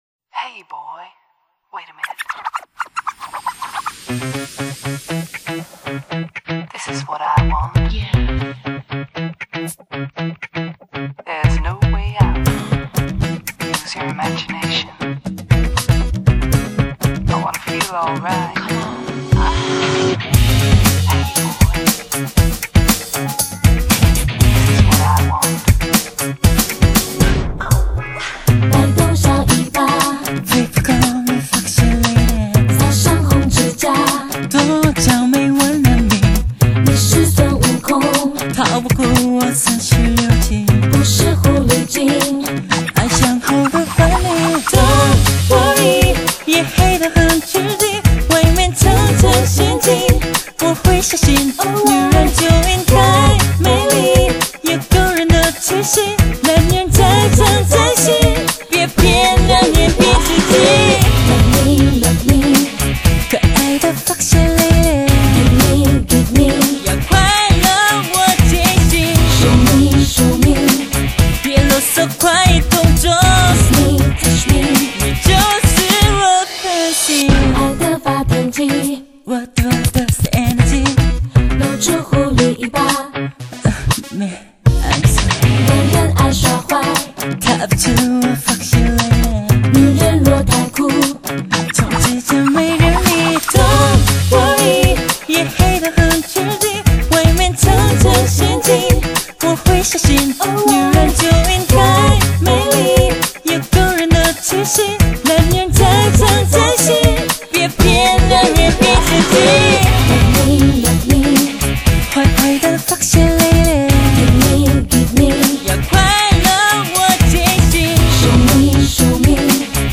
音乐风格以纯种美式R&B嘻哈舞曲为主题。
2005新春最狐媚、美式Hi-Hop、R&B舞曲将盘旋于耳边，余音绕梁。
整张专辑包含嬉哈、R＆B曲风。